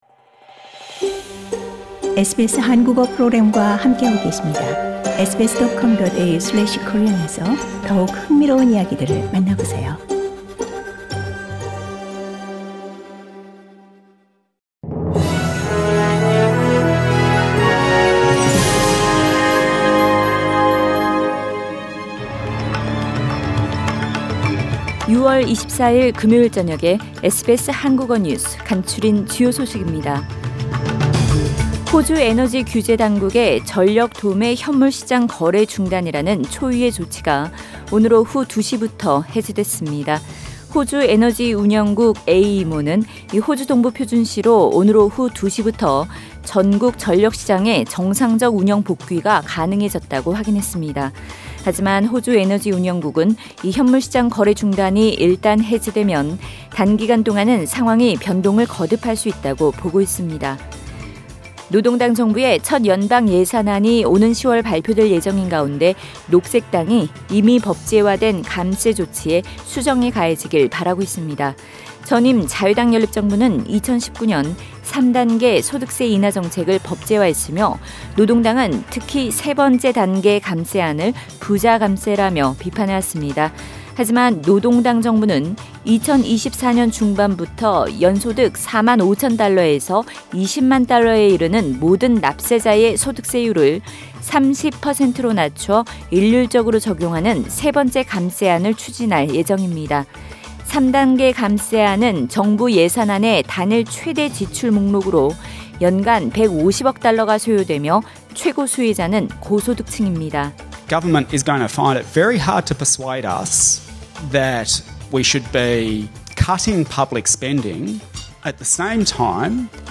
SBS 한국어 저녁 뉴스: 2022년 6월 24일 금요일
2022년 6월 24일 금요일 저녁 SBS 한국어 간추린 주요 뉴스입니다.